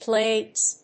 発音記号・読み方
/plegz(米国英語), pleɪgz(英国英語)/